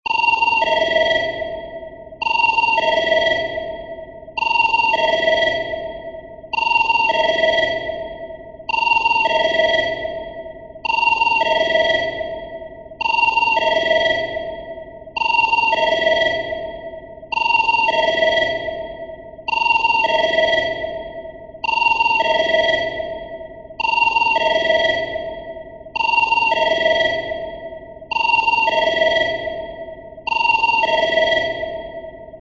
エコーフォンの電話の着信音は、非常に印象的であり、ビジネス環境にふさわしい洗練された響きを持っています。
温かみがあり、親しみやすい印象。